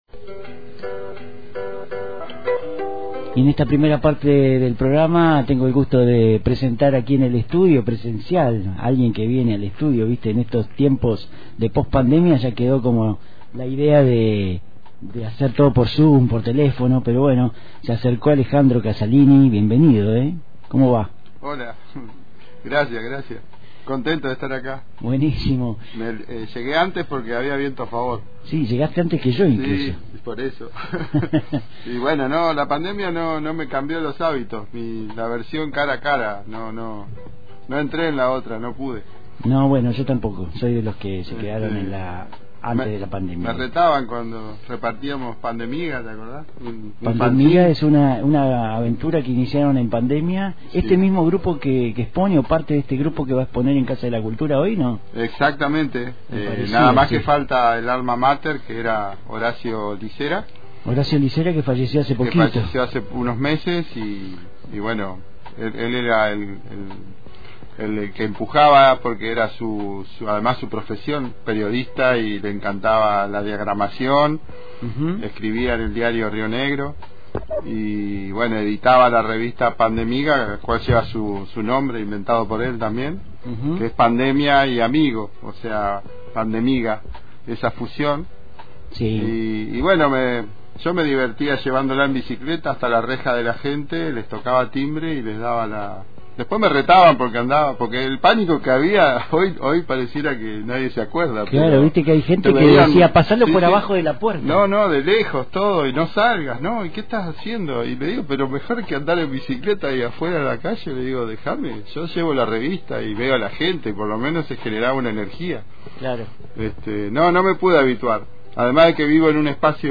Arte a partir de objetos reciclados, el Clú inaugura la muestra «Tierras» – Antena Libre FM 89.1 Mhz